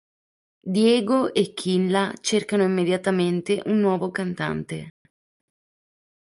im‧me‧dia‧ta‧mén‧te
/im.me.dja.taˈmen.te/